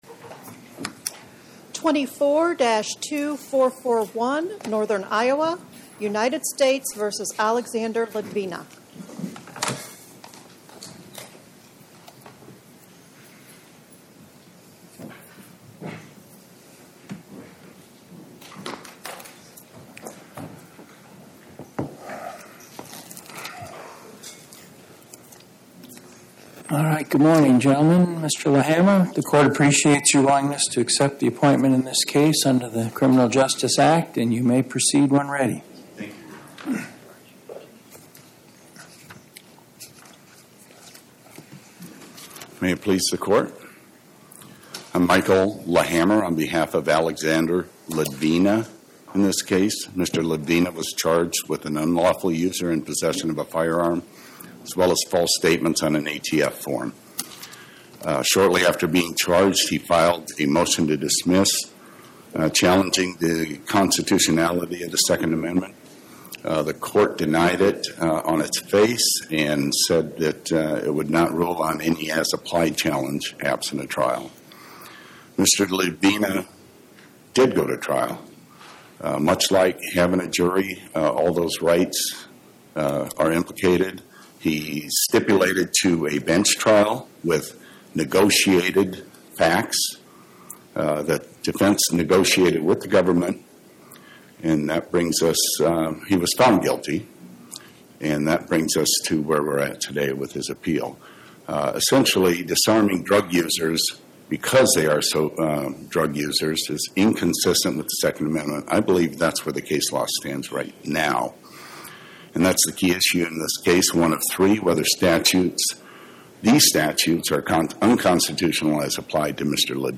Oral argument argued before the Eighth Circuit U.S. Court of Appeals on or about 09/19/2025